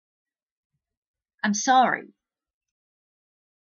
もう一つ、巻き舌Rと喉R の違いがよくわかる例を、聞いてみてください。
まずは、なんちゃってR から。
次は、喉発音です。